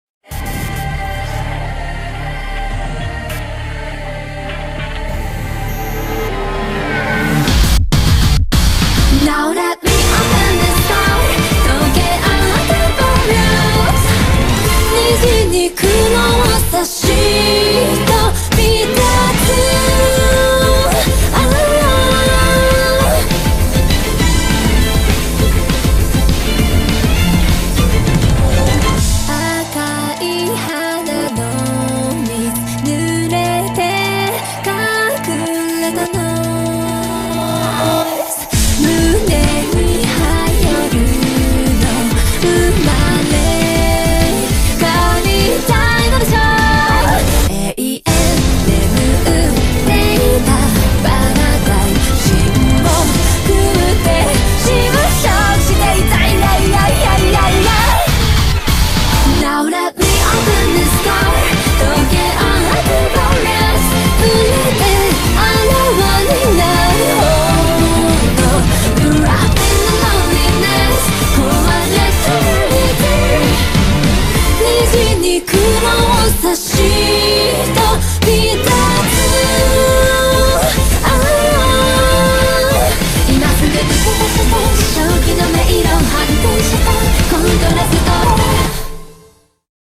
BPM201
Audio QualityMusic Cut